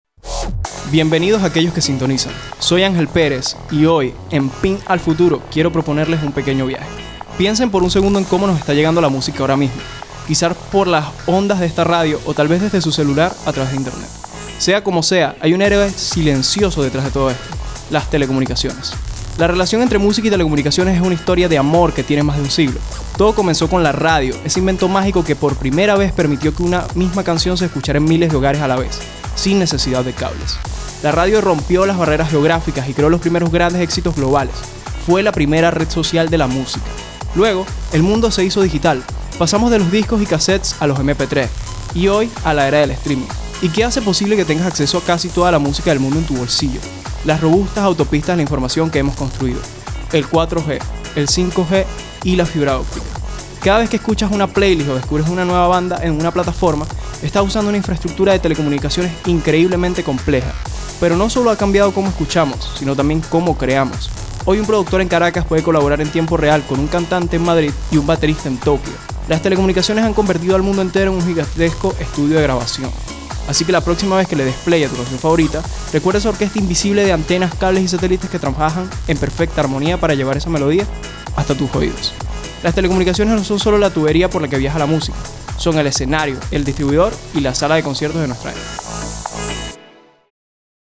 Estudiantes de la carrera de Ingeniería de Sistemas